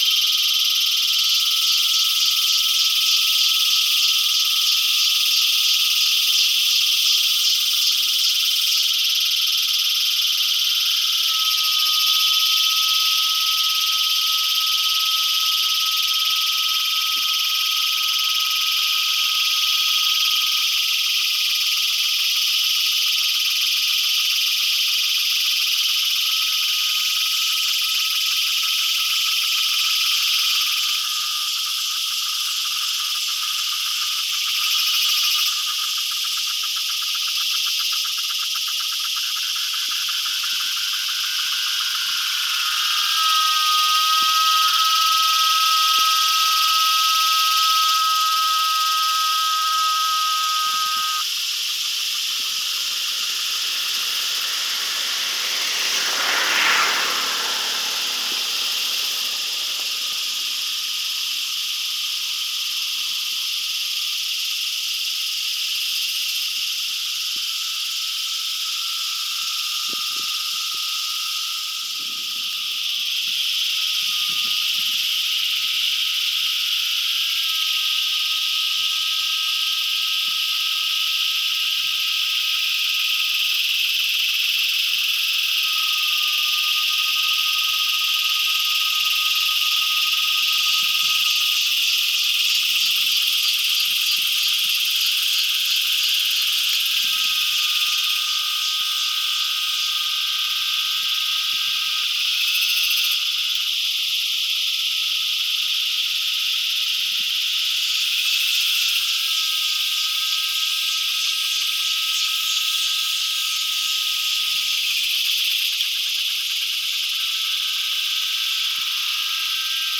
Recorrido sonoro por nuestro país
esa-formosa-ciudad-de-formosa-reserva-de-biosfera-laguna-oca.mp3